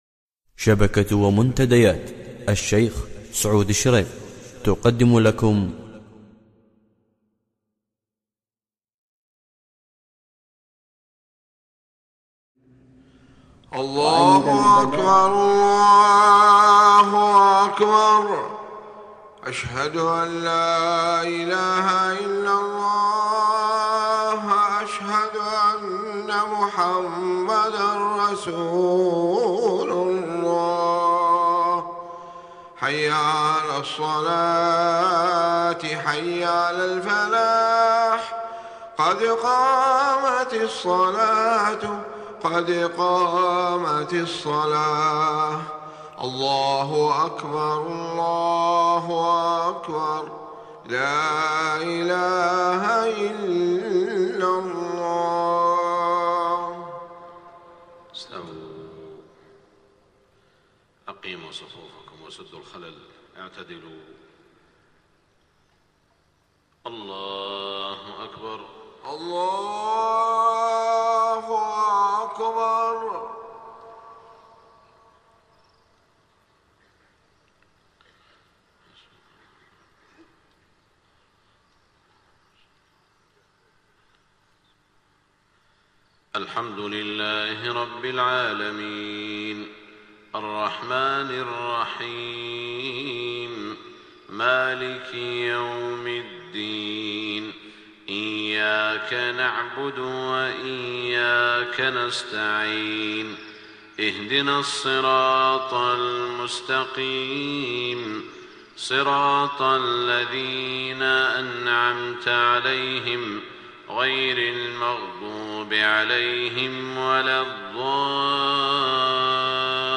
صلاة الفجر 5-3-1428هـ من سورة التوبة 97-107 > 1428 🕋 > الفروض - تلاوات الحرمين